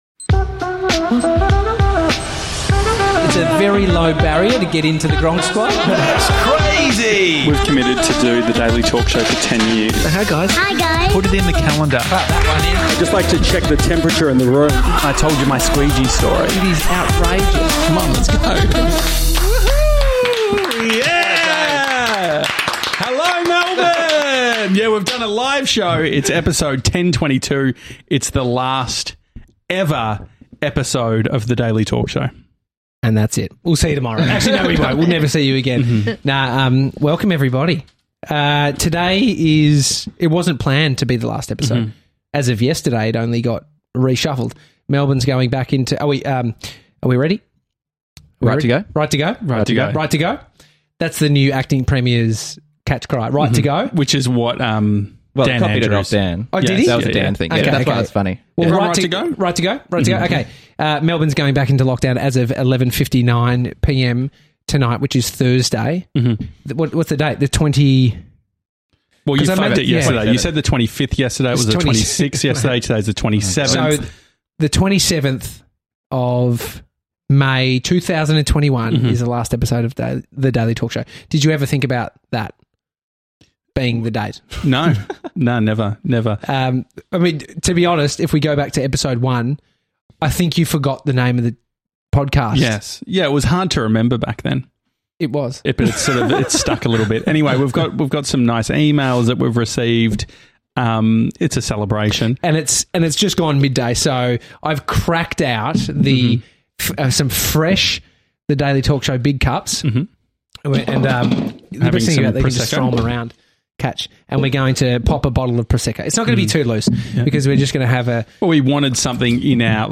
an Australian talk show and daily podcast